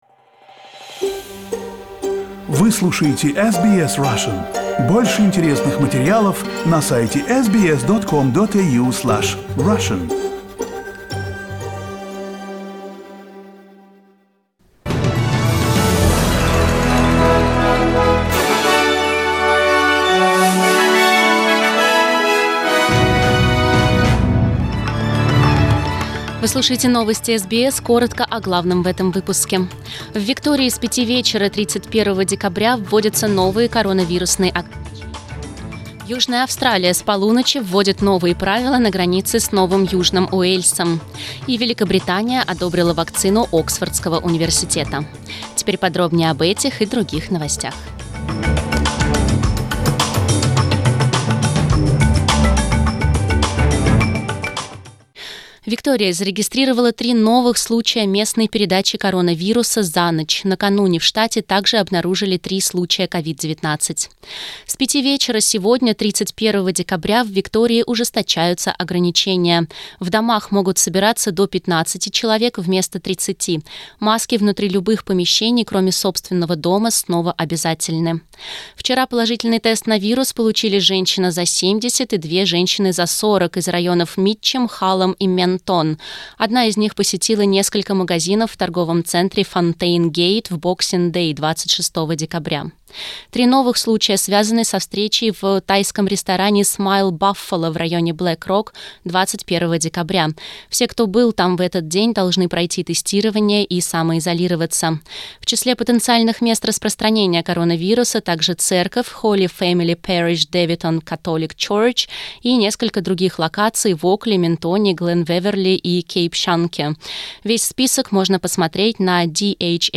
Новостной выпуск за 31 декабря